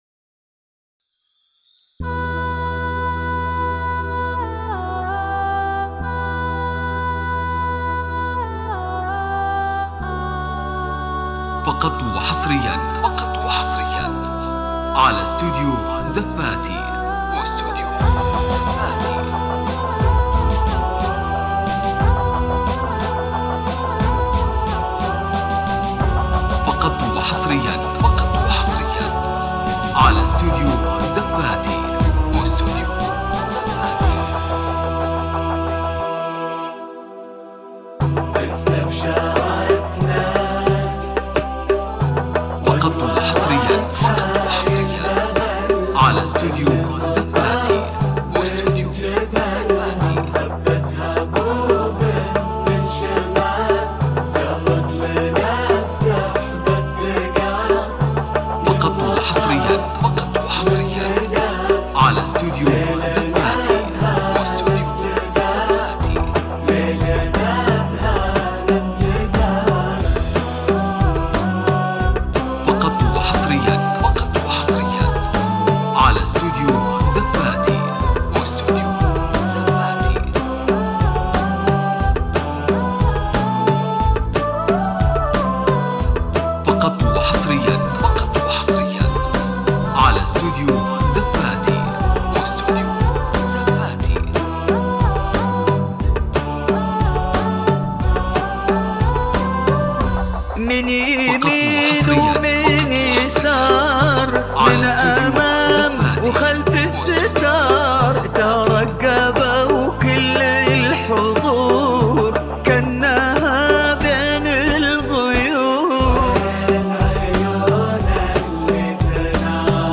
بدون موسيقى نسخة ايقاعات